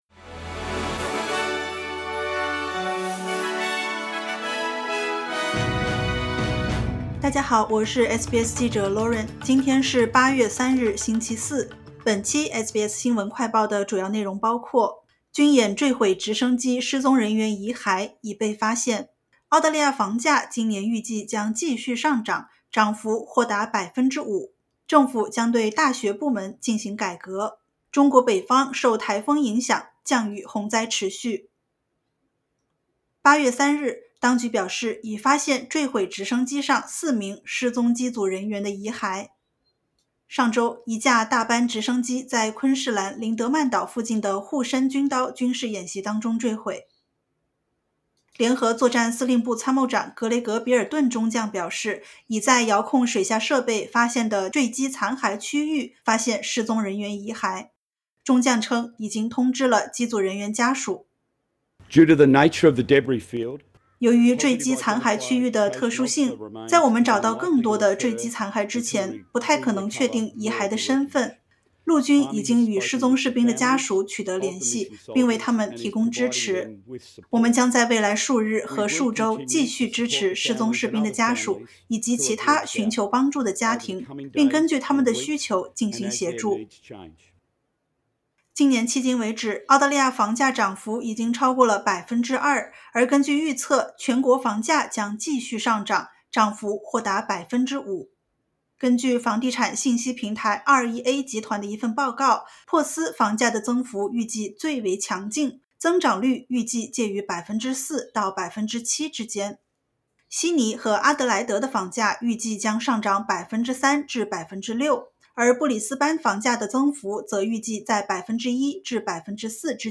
【SBS新闻快报】“护身军刀”军演坠机失踪人员遗骸已被发现